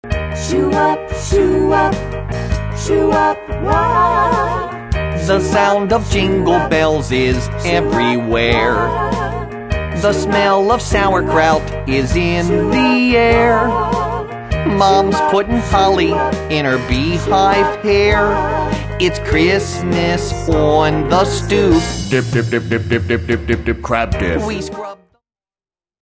A Do-Wop Holiday Song.
This hilarious collection of novelty songs includes